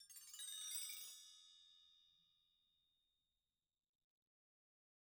BellTree_Stroke2_v1_Sum.wav